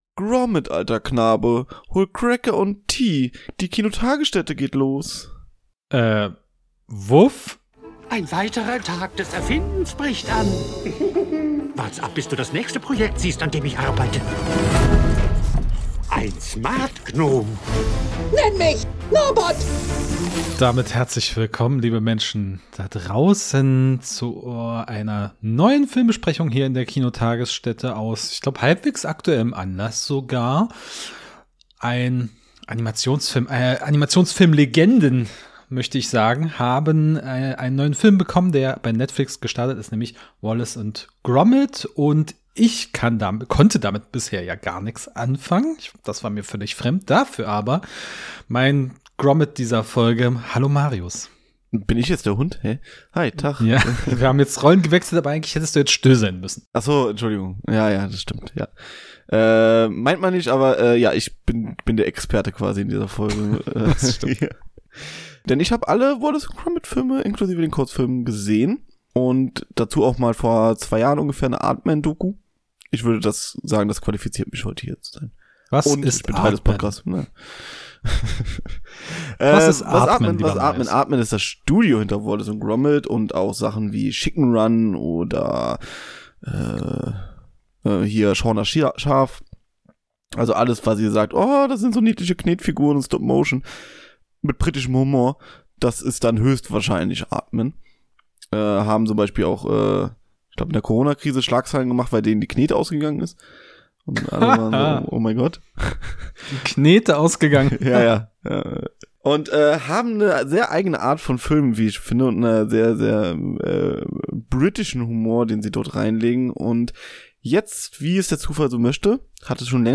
Wallace & Gromit: Vergeltung mit Flügeln | Review-Talk ~ Die Kinotagesstätte Podcast